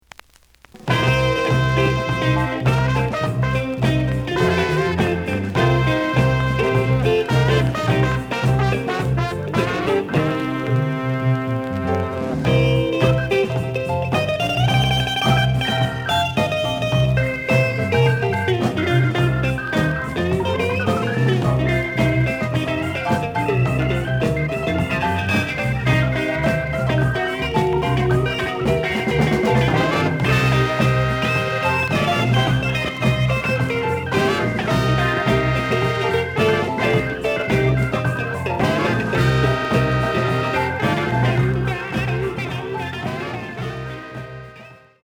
The listen sample is recorded from the actual item.
●Genre: Soul, 70's Soul